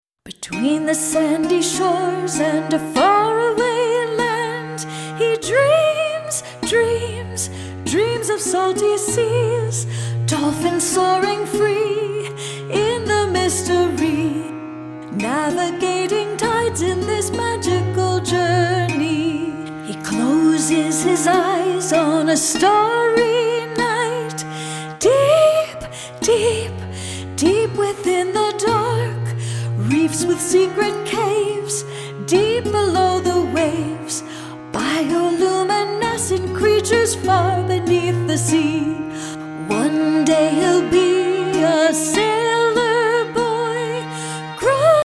new concept in children's music